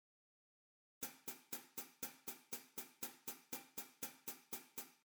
もっとも基本となるシンバルで、リズムを刻むのに使います。
ハイハット・クローズ
hhclosed.mp3